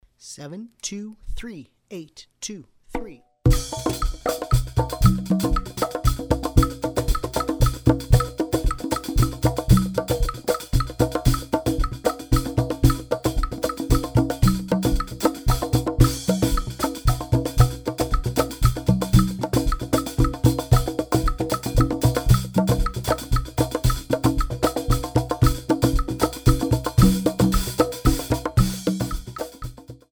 The music combines various percussion instruments,
Medium Triple Meter
Medium Triple Meter - 115 bpm